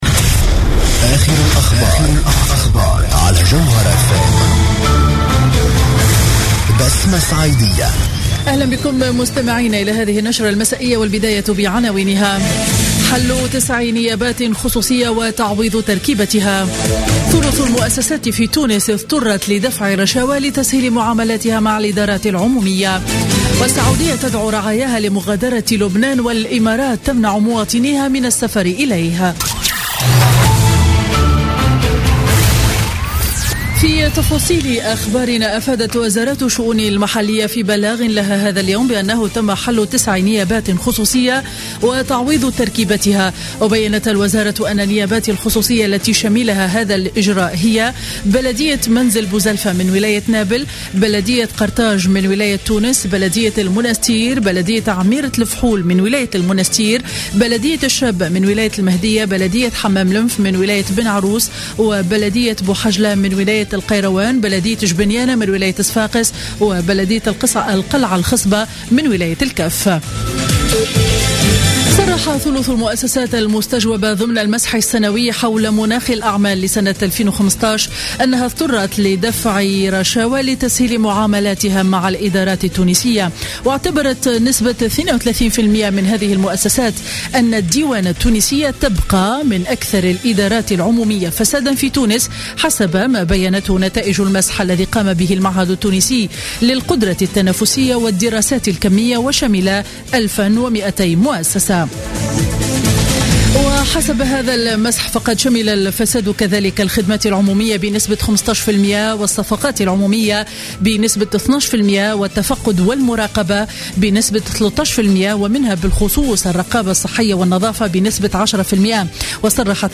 نشرة أخبار السابعة مساء ليوم الثلاثاء 23 فيفري 2016